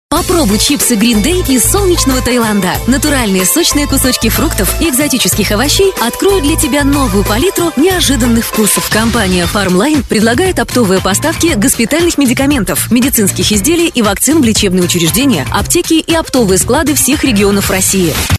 Professional Russian Femael talent for any Russin VO work............